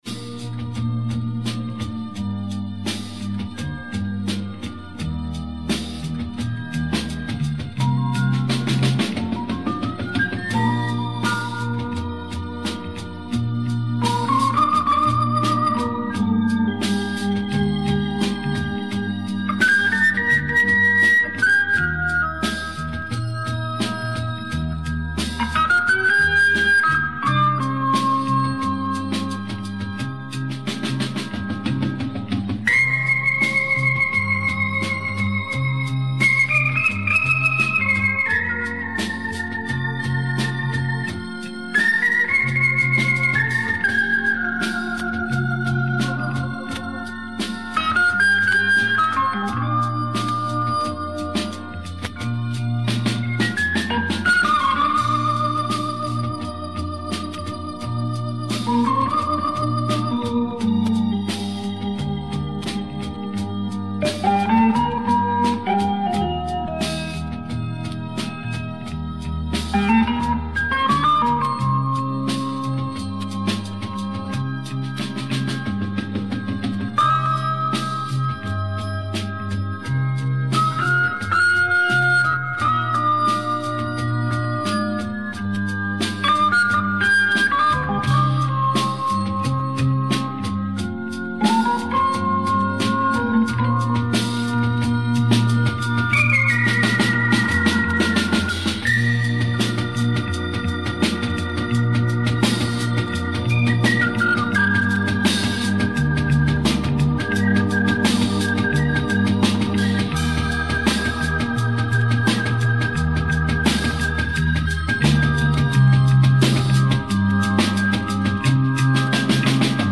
En 1975, j’ai enregistré sur mon magnétophone stéréo Phillips plusieurs titres de notre répertoire.
Hélas, les bobines très fragiles n’ont pas résisté à l’usure du temps.
J’ai pu restaurer un slow avec mon logiciel Magic Audio cleanic MX joué alors sur mon orgue Hammond B3, cabine Leslie, (Mon plus beau souvenir) je le soumets à votre écoute ci-dessous.